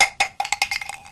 izanagi-2022 / assets / sfx / weapons / shell-12gauge1 / var2.wav